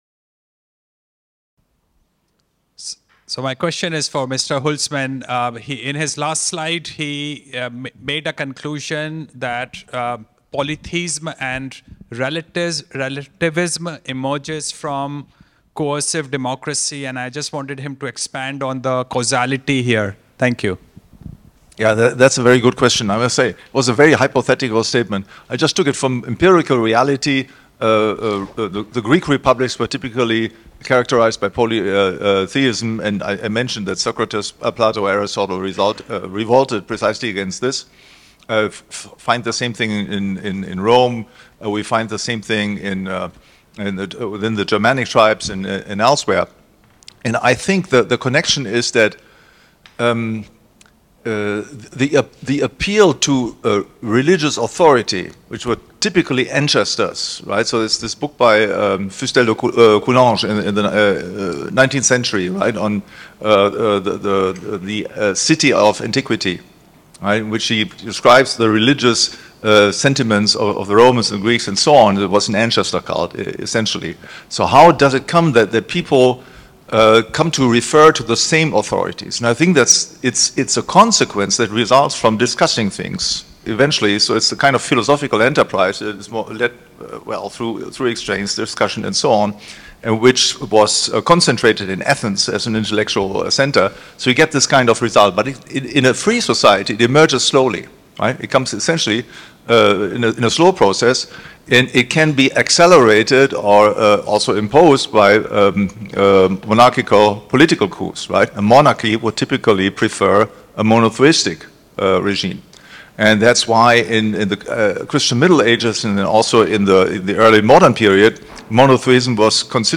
This panel discussion is from the 18th annual 2024 Annual Meeting of the PFS (Sept. 19–24, 2024, Bodrum, Turkey).